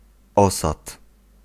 Ääntäminen
Synonyymit gîte Ääntäminen France: IPA: /ʒiz.mɑ̃/ Haettu sana löytyi näillä lähdekielillä: ranska Käännös Ääninäyte 1. osad {m} 2. złoże {n} Suku: m .